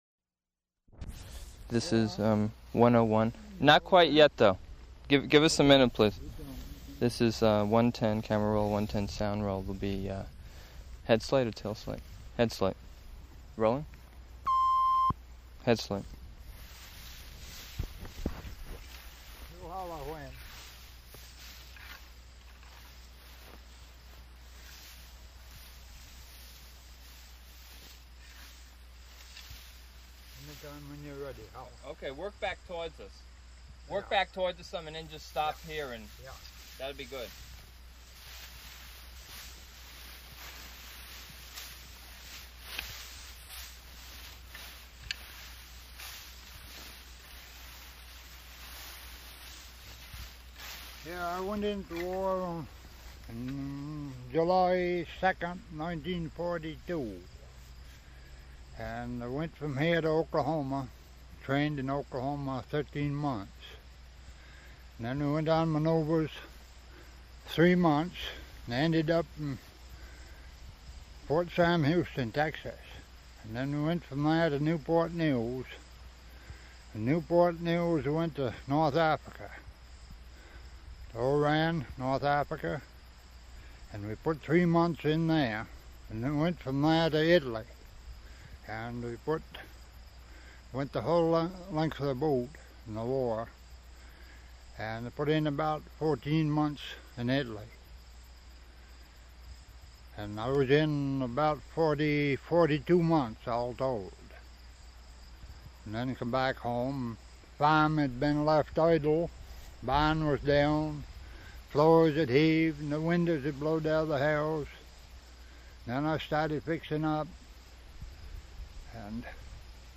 Format 1 sound tape reel (Scotch 3M 208 polyester) : analog ; 7 1/2 ips, full track, mono.